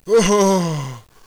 warrior_die4.wav